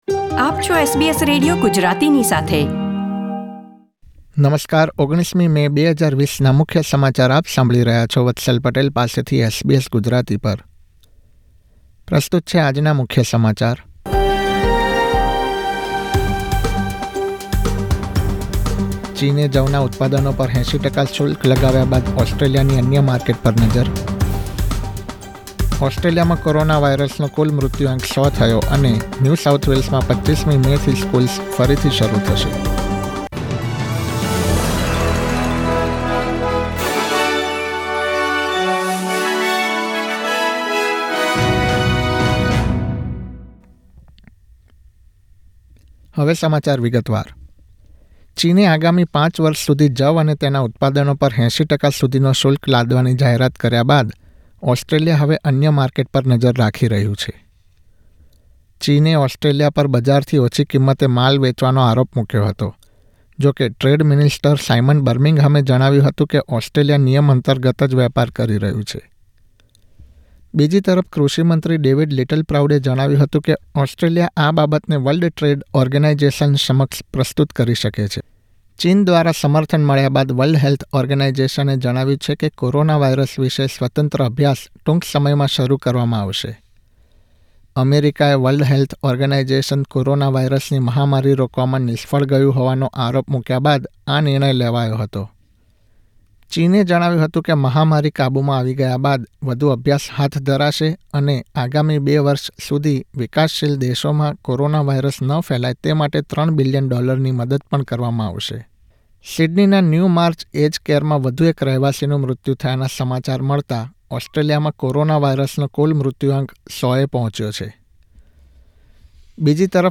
SBS Gujarati News Bulletin 19 May 2020